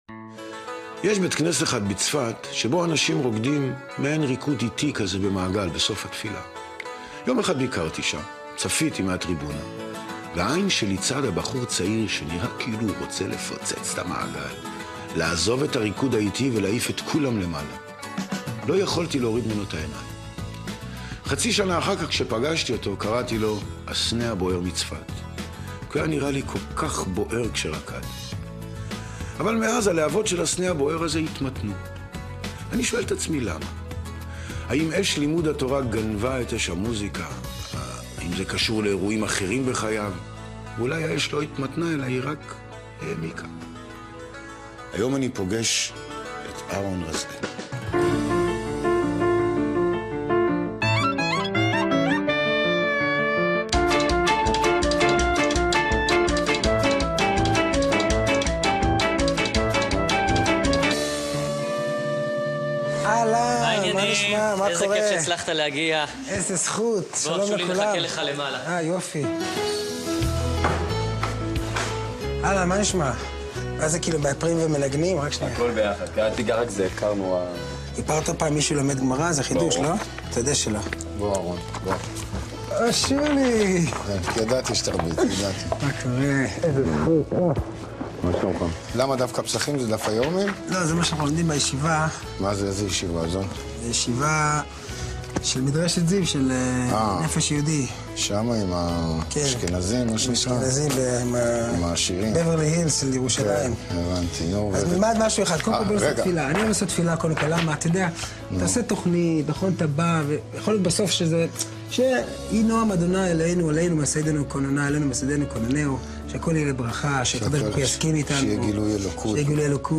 שולי רנד משוחח עם אהרן רזאל על מסלול חייו - העקשנות של הוריו לא לפספס את האימון היומי על הפסנתר לפני שהוא יוצא לשחק עם החברים, החזרה בתשובה של משפחתו, הלימודים באקדמיה למוזיקה, השירות הצבאי בלהקות צבאיות, ומשם לתחילת הקריירה המוזיקלית בצפת שנמשכת עד היום. קבצים מצורפים הסנה הבוער מצפת - שולי רנד מארח את אהרן רזאל.mp3 41.4 MB · צפיות: 287